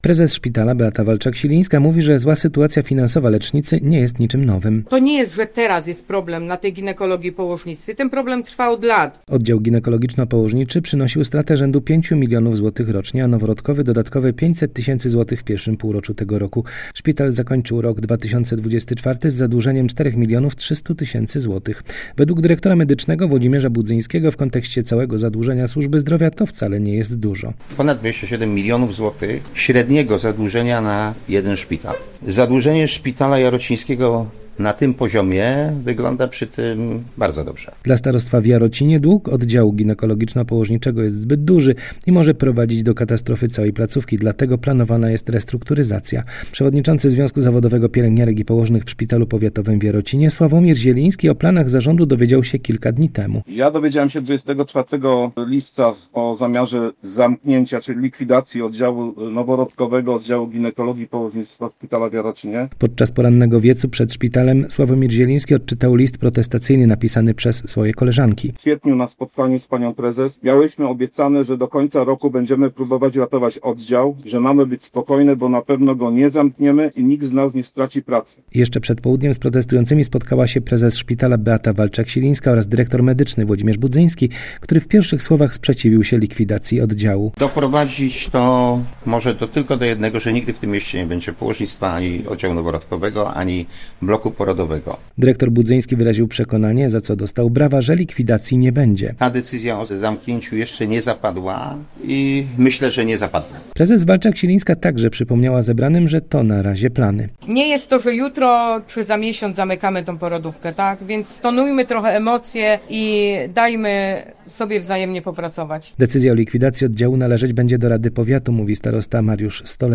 Plany naprawy sytuacji finansowej szpitala powiatowego w Jarocinie poprzez restrukturyzację zaskoczyły załogę. Rano zwołano spontaniczny protest przed wejściem do szpitala.